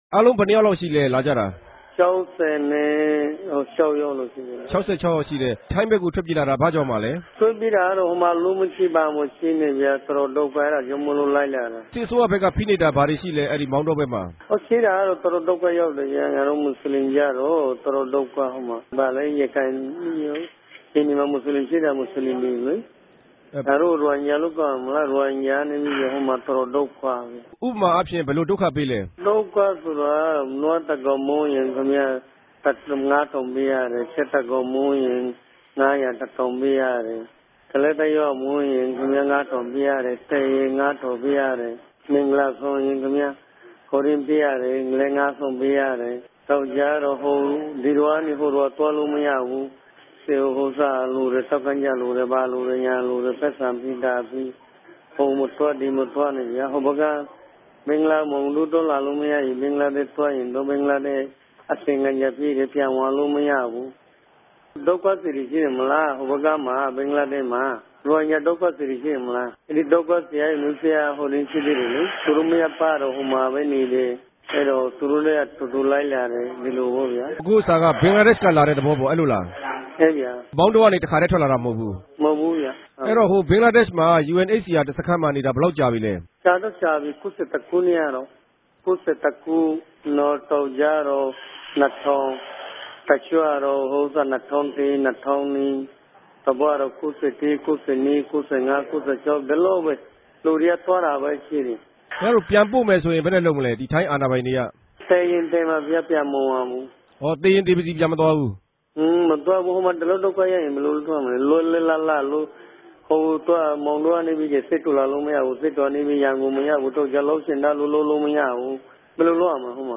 ထိုင်းိံိုင်ငံ ဗန်ကောက်္ဘမိြႚ RFA႟ုံးခြဲကနေ ဆက်သြယ်မေးူမန်းခဲ့ပၝတယ်။